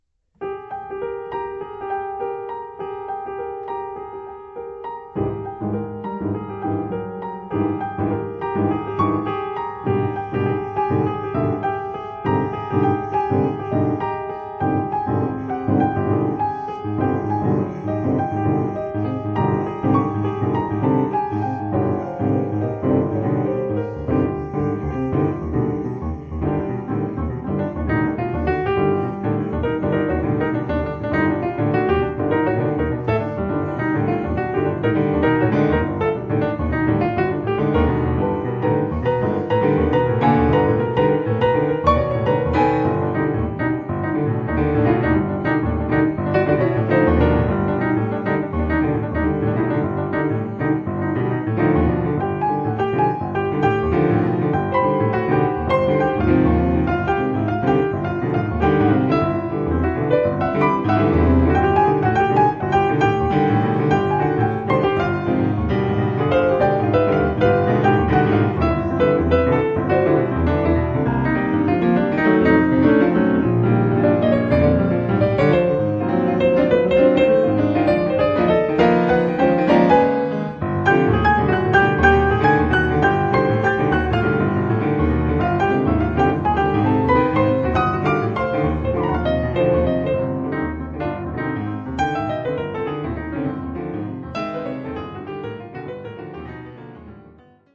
pianos
at Studio La Buissonne